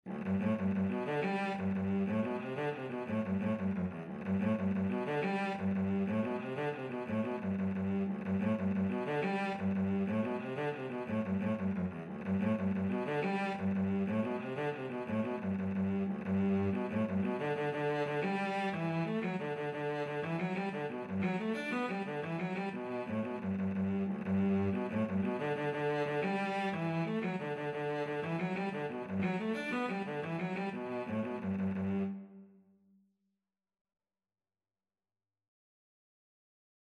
6/8 (View more 6/8 Music)
D3-D5
G major (Sounding Pitch) (View more G major Music for Cello )
Cello  (View more Intermediate Cello Music)
Traditional (View more Traditional Cello Music)